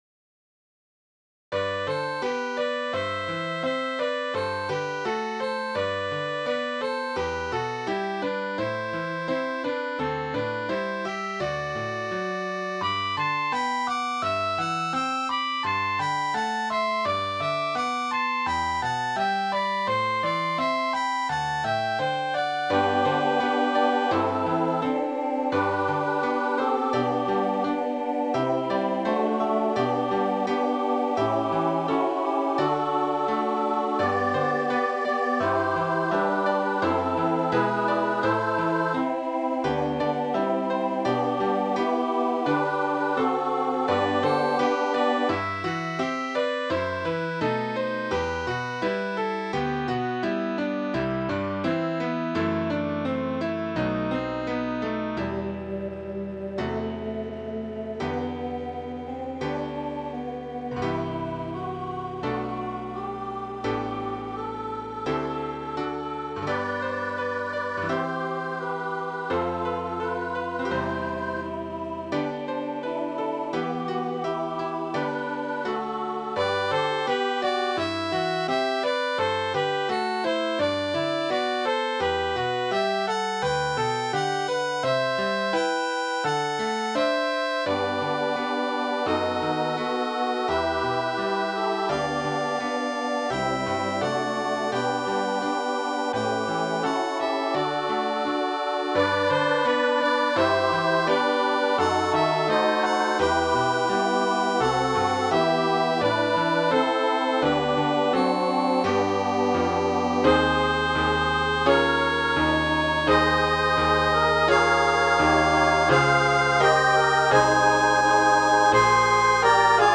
Lead Me Into Life Eternal, SSA with optional flute and violin duet
Voicing/Instrumentation: SSA , Trio We also have other 4 arrangements of " Lead Me Into Life Eternal ".